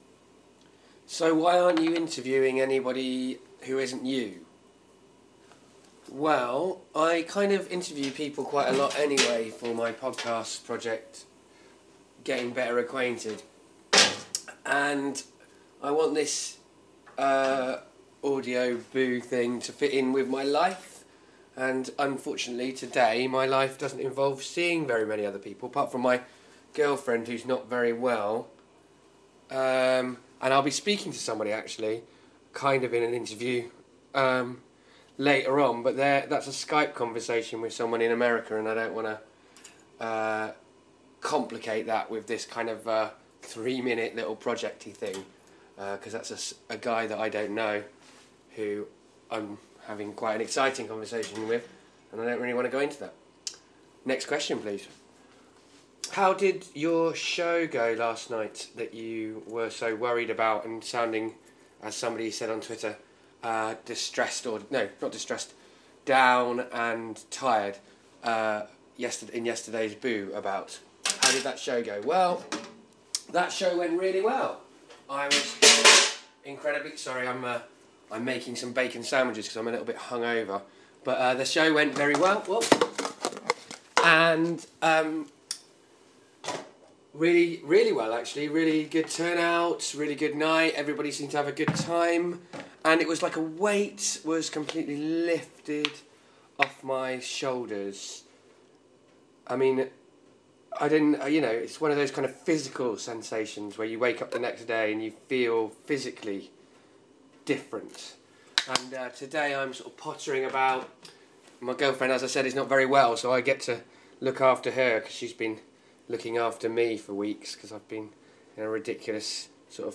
FeBOOary 7: interview